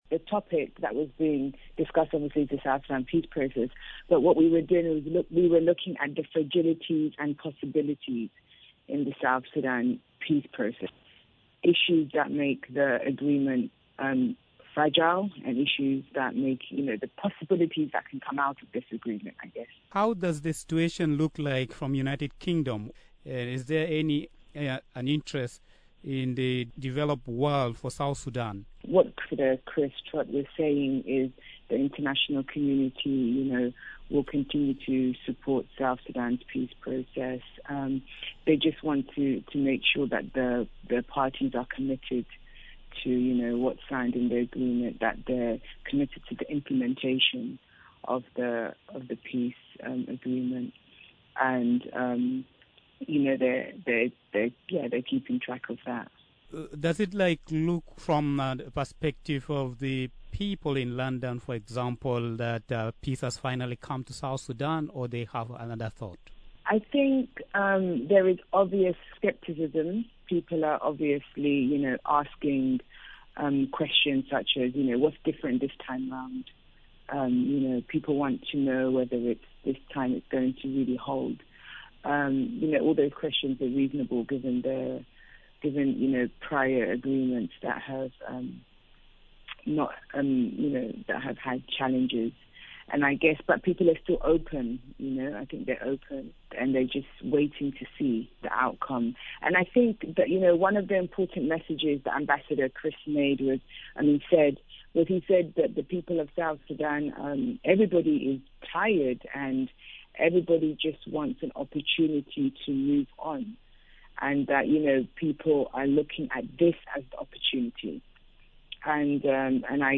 In an interview